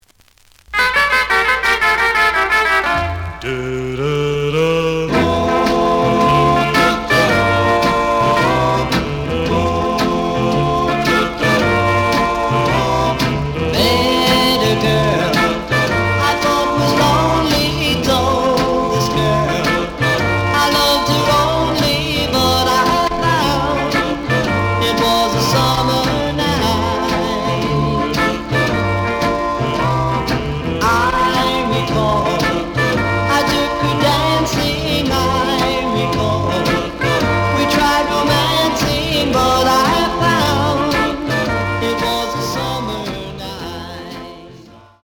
試聴は実際のレコードから録音しています。
●Genre: Rhythm And Blues / Rock 'n' Roll
●Record Grading: EX- (盤に若干の歪み。多少の傷はあるが、おおむね良好。)